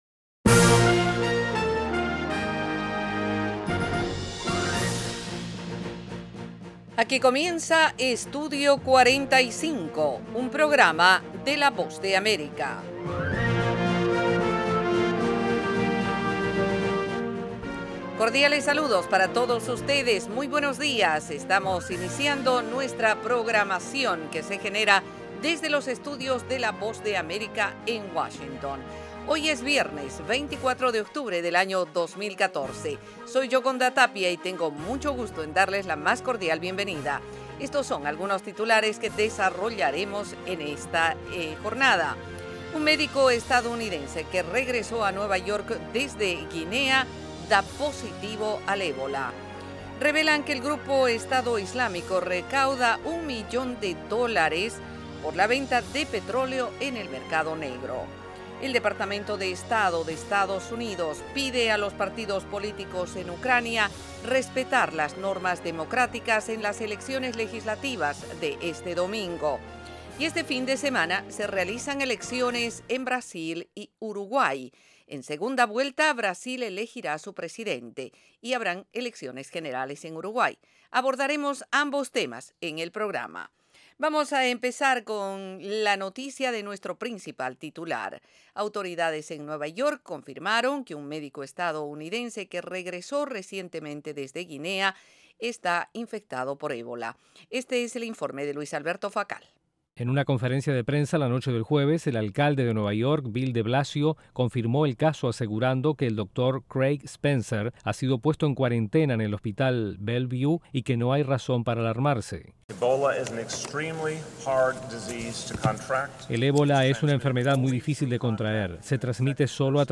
El programa ofrece -en 30 minutos- la actualidad noticiosa de Estados Unidos con el acontecer más relevante en América Latina y el resto del mundo.